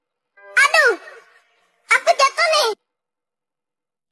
Efek suara Aduh aku jatuh ni
Kategori: Suara viral
Keterangan: Efek suara meme Aduh aku jatuh ni sering digunakan dalam meme dan edit video untuk menambah humor.